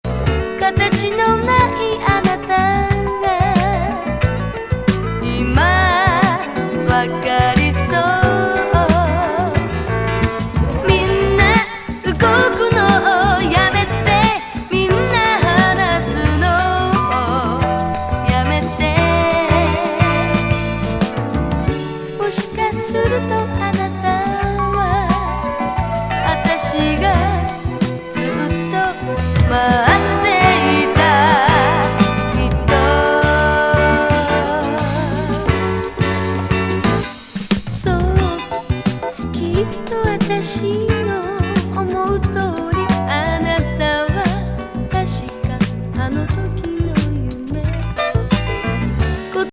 スムース・メロウ・ソウル・グルーヴ!